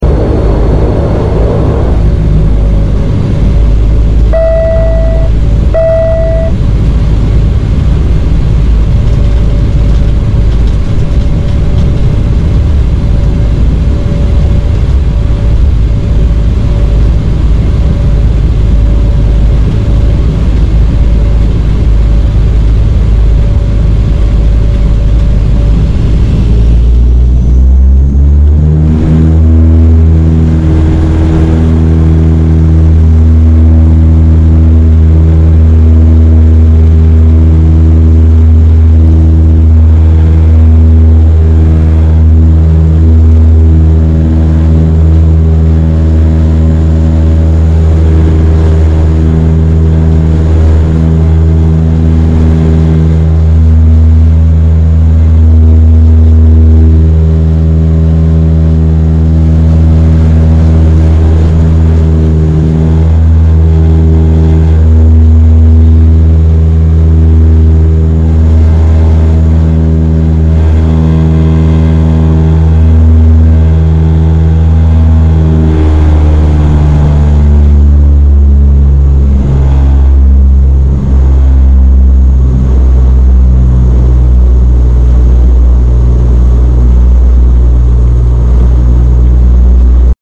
Taking off in a Dash sound effects free download
Taking off in a Dash 8-102!!!